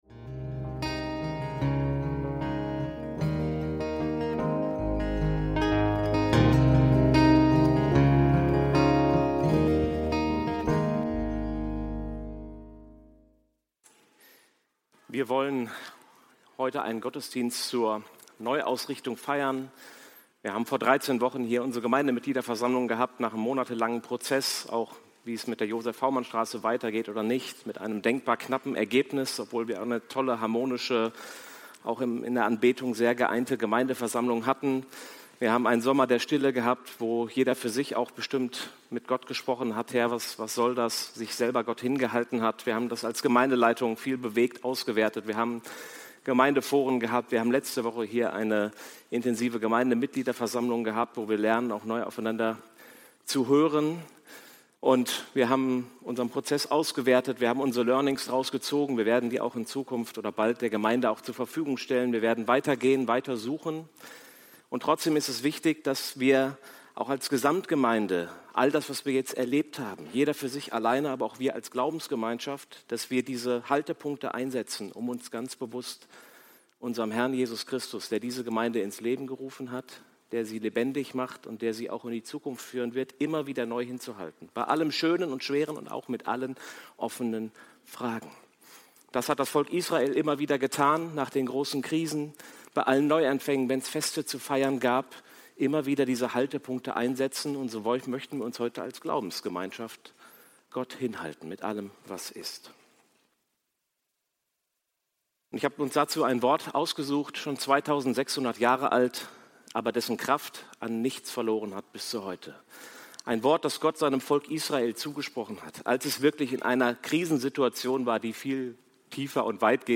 Neuausrichtung – Predigt vom 28.09.2025